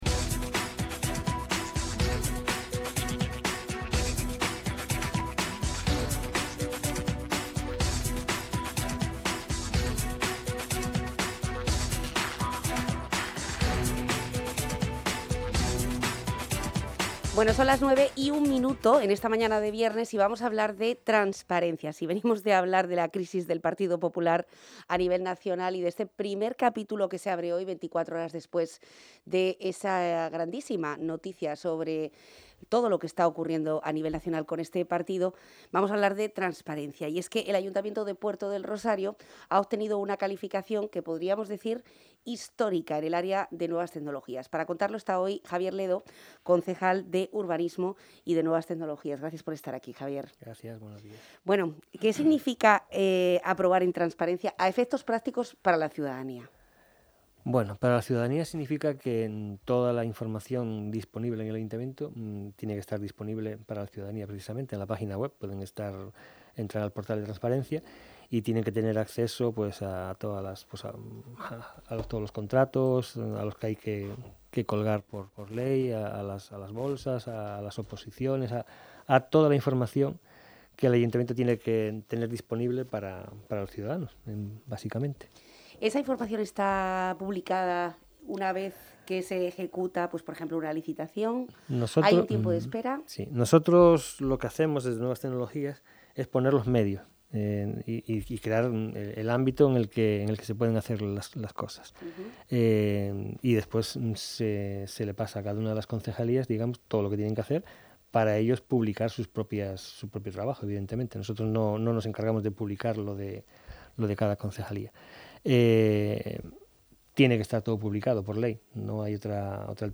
El Ayuntamiento de Puerto del Rosario aprueba en Transparencia. Y de ello ha hablado el concejal del área Javier Ledo en El Magacín de Onda Fuerteventura.